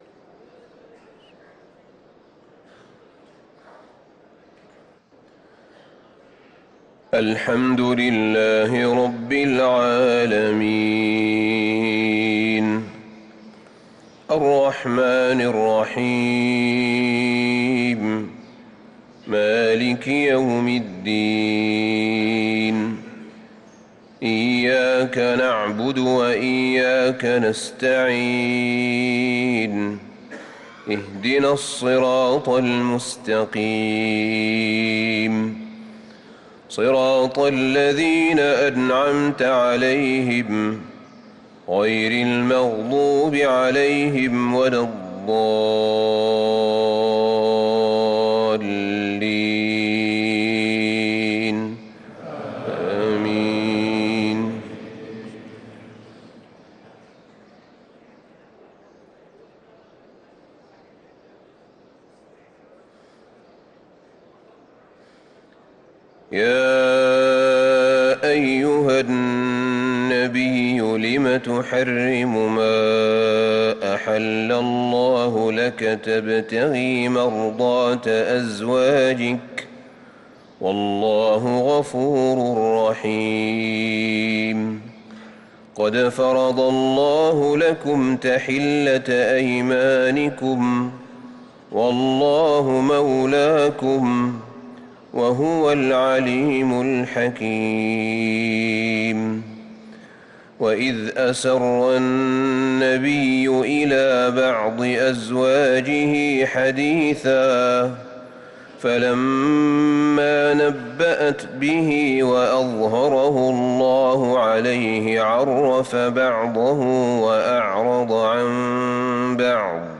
صلاة الفجر للقارئ أحمد بن طالب حميد 5 رجب 1445 هـ
تِلَاوَات الْحَرَمَيْن .